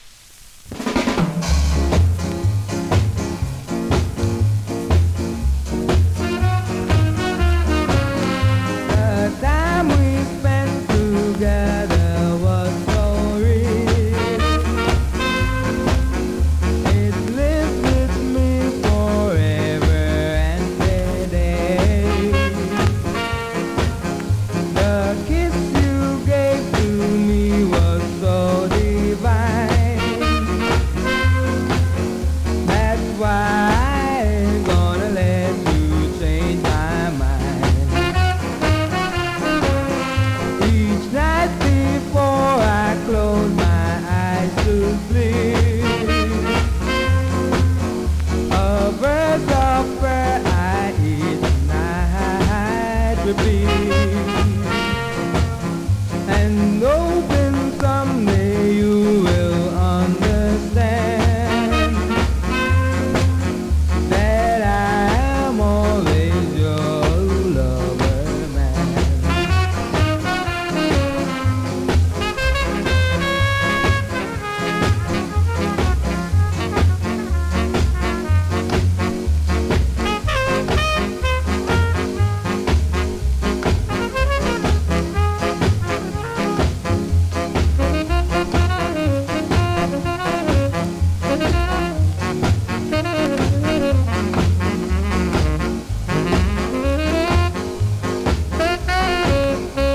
コメントレアSKA!!
スリキズ、ノイズ比較的少なめで